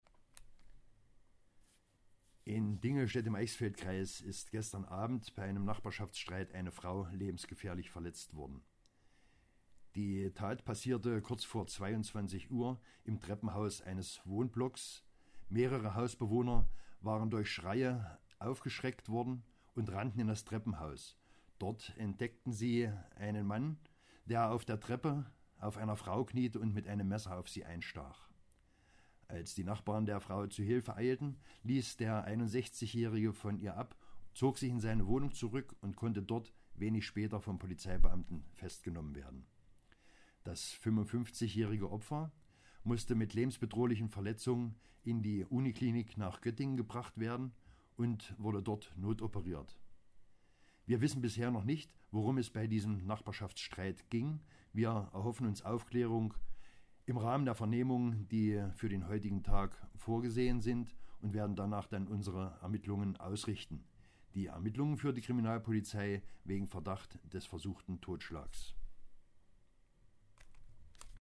Einzelheiten von Polizeisprecher